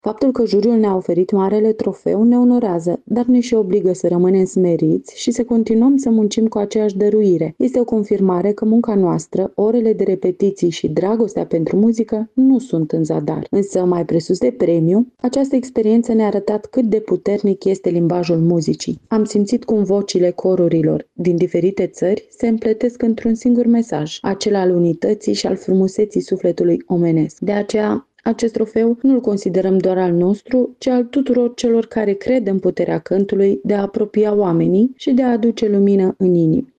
într-o declarație pentru postul nostru de radio